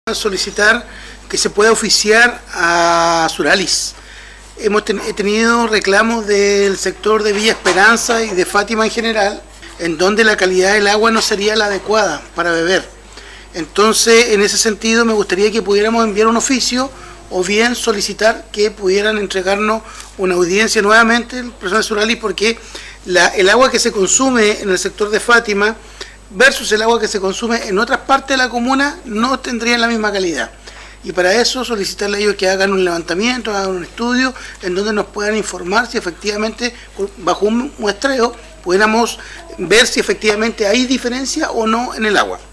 Acerca de la problemática que aqueja a vecinos de Ancud desde hace semanas, en torno a la mala calidad del agua por la turbiedad que presenta, se trató también en la mesa del concejo municipal, en la reciente sesión ocurrida el lunes de esta semana.
15-CONCEJAL-ANDRES-IBANEZ.mp3